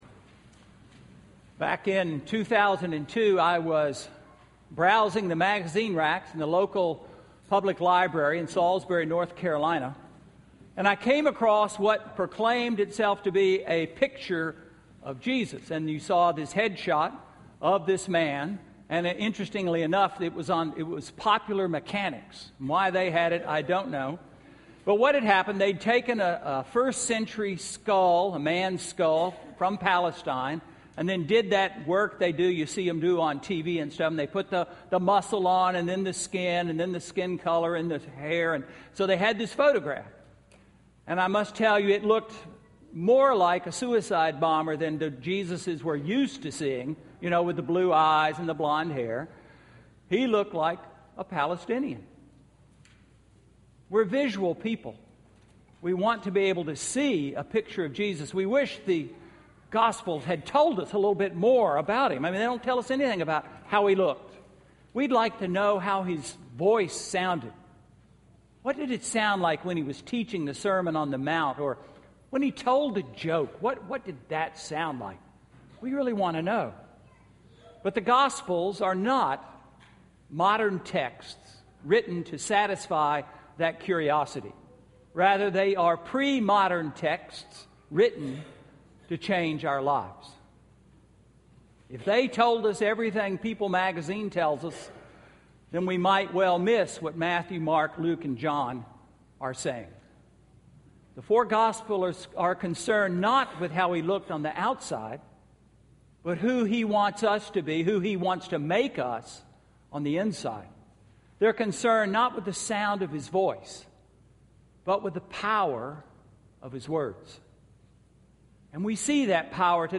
Sermon–January 26, 2014 – All Saints' Episcopal Church
Sermon–January 26, 2014